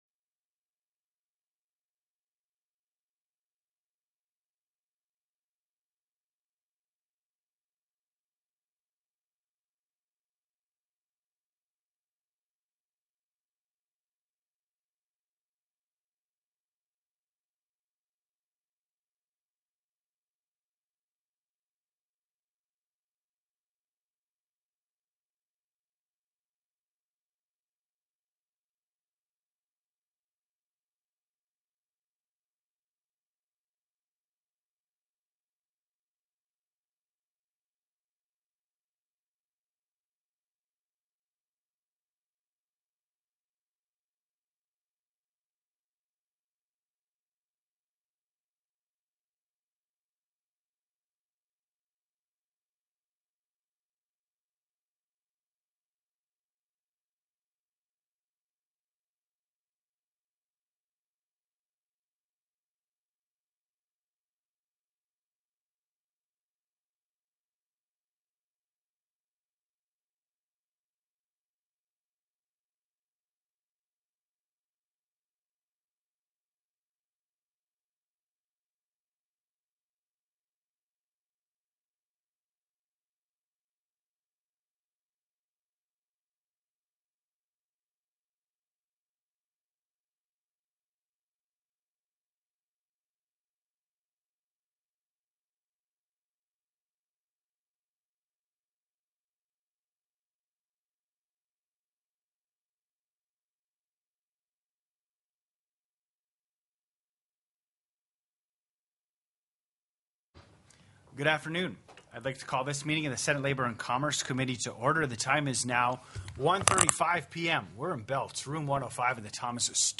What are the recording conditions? The audio recordings are captured by our records offices as the official record of the meeting and will have more accurate timestamps. SB 150 NET METERING PROGRAM & FUND TELECONFERENCED Heard & Held